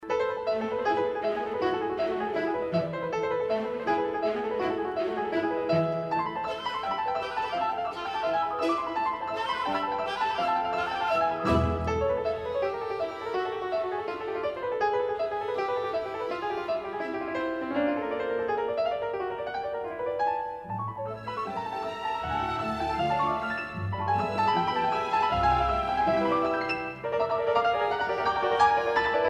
活泼的，钢琴表现主题，木管与弦乐表现片断